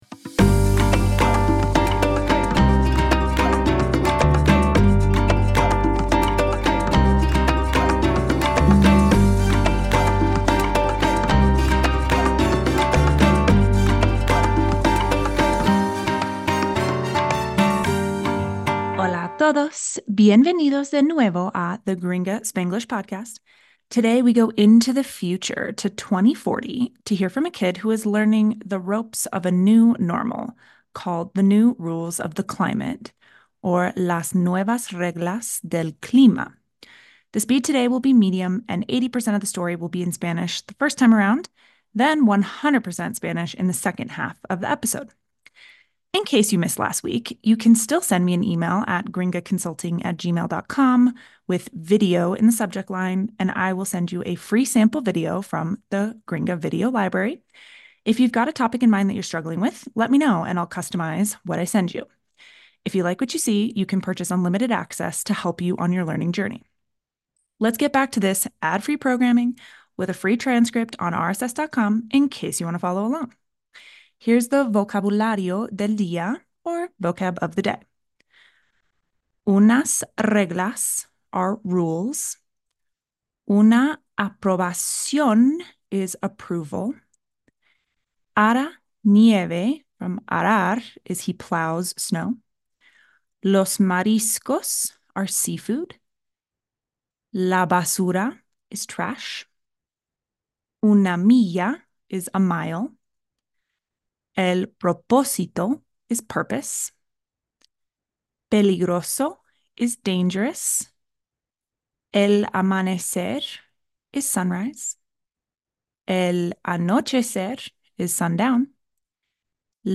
S03 E21 - Las nuevas reglas del clima - Medium Speed - 80% Spanish